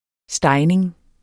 Udtale [ ˈsdɑjneŋ ]